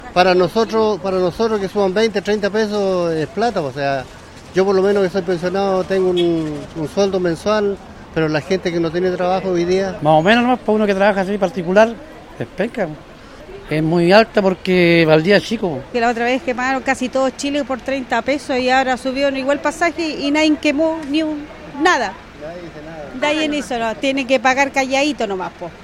En tanto, otros están disconformes con el alza, sobre todo porque consideran que están obligados a pagar y el servicio no está acorde al aumento del precio.